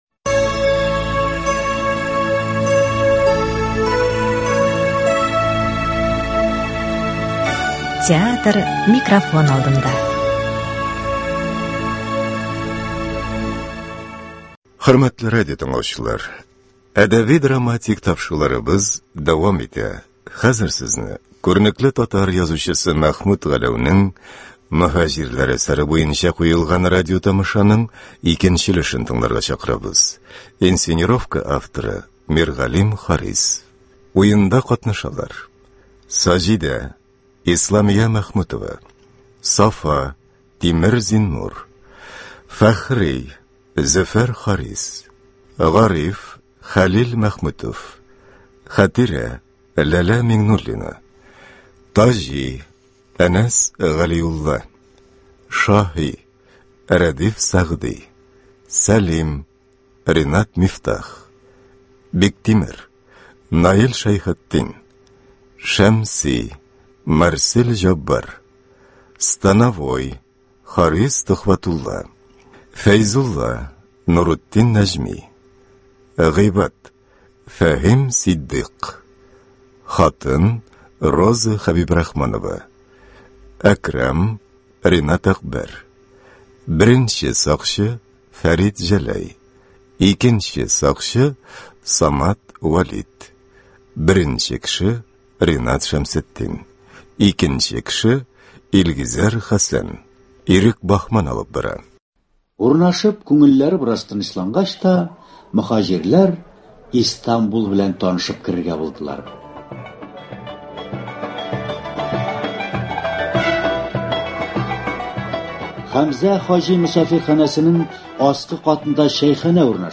Сезне күренекле татар язучысы Мәхмүт Галәү әсәре буенча эшләнгән “Мөһаҗирләр” дигән радиопостановка тыңларга чакырабыз.